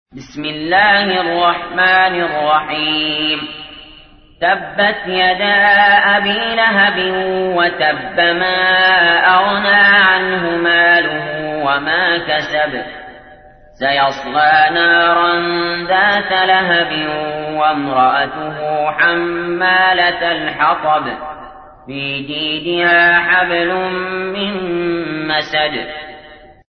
تحميل : 111. سورة المسد / القارئ علي جابر / القرآن الكريم / موقع يا حسين